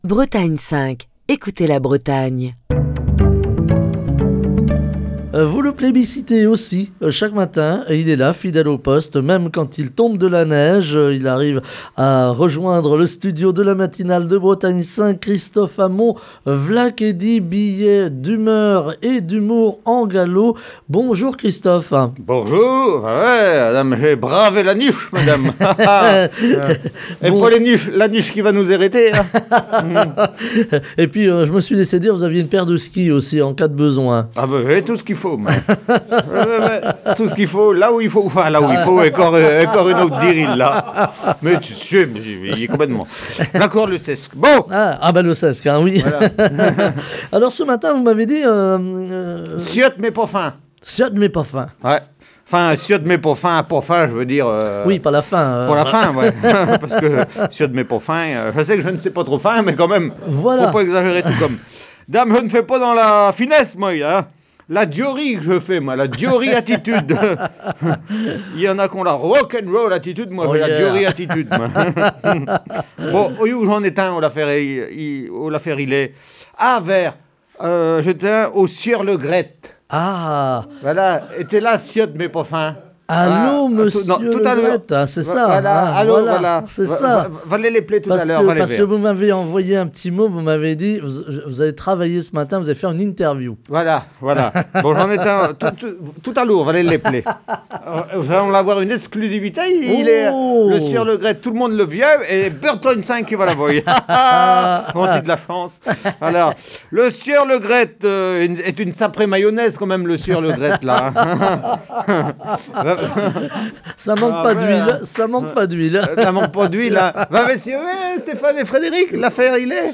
Chronique du 18 janvier 2023.